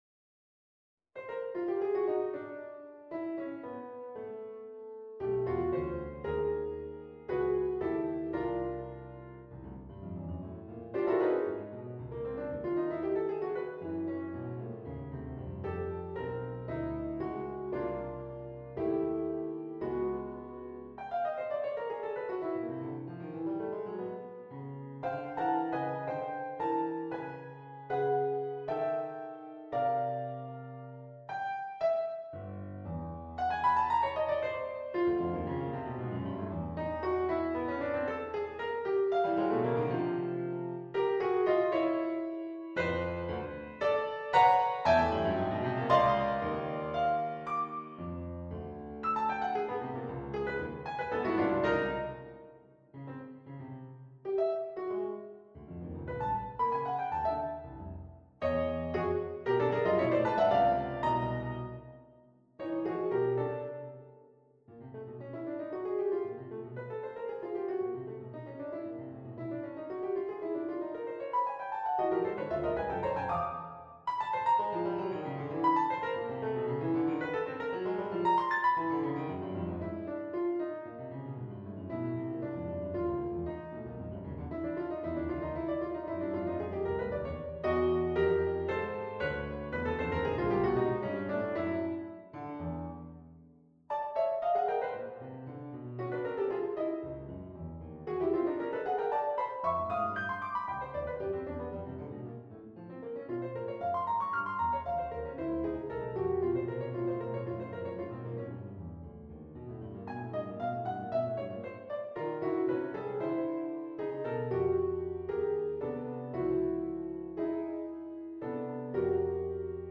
on a purpose-selected tone row
The open question comes down to this: is it just lazy, uninventive hack work - 12 simple variations on a tone row; using the same basic rhythmic patterns again and again, with barely a triplet to be seen and only three dotted rhythms anywhere that immediately evaporate back into plodding 4/4 predictability? Or is it a rich, complex and compact exploration of every possible 2-, 3-, and 4-voice harmonic progression - root, retrograde, inversion, and retrograde inversion - extractable from each iteration of the row, changing tonal centers and melodic variations subtly every thirty seconds like clockwork?
C-Bb-F-G-Ab-Gb-Eb-D-E-Db-B-A